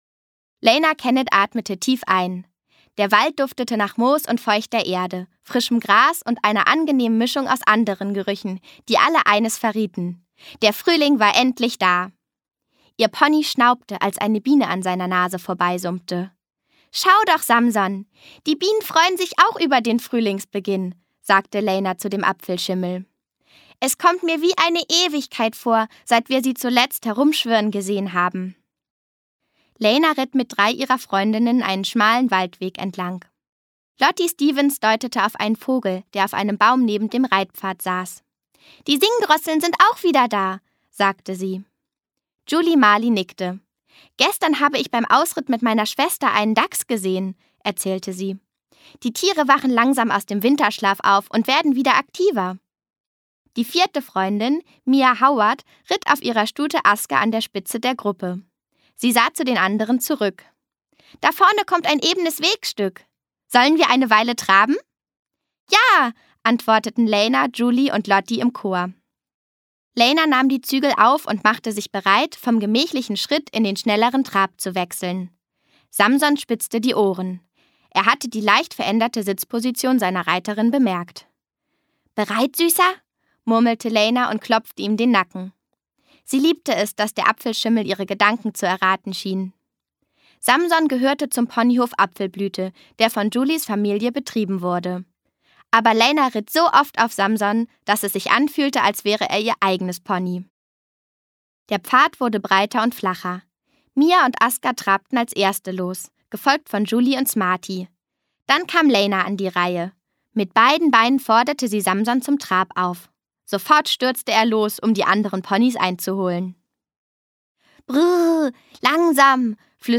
Die Reihe Ponyhof Apfelblüte spricht sie lebhaft und authentisch.